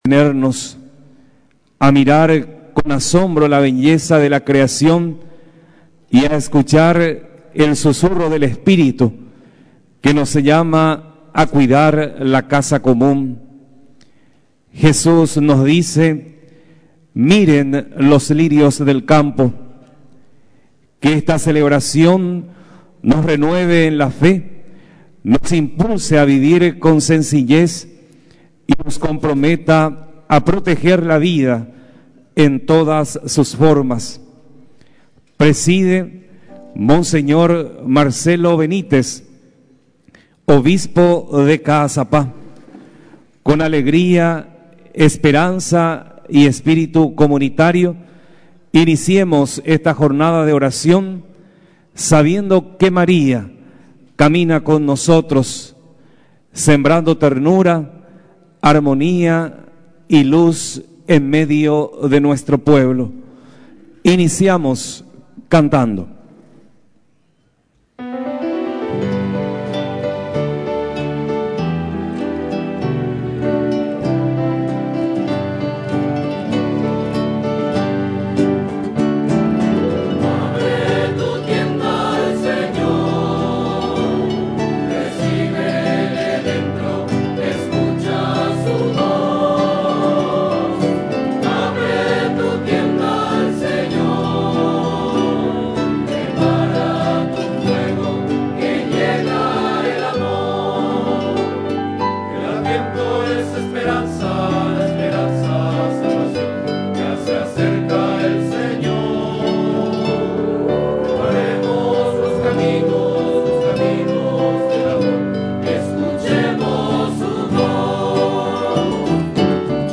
En el quinto día del novenario a la Virgen de Caacupé, el obispo de Caazapá, monseñor Marcelo Benítez, recordó que el hombre es custodio de la naturaleza por mandato divino, pero no cumple ese rol porque la tierra sufre por la depredación de los recursos naturales que sólo traen miseria.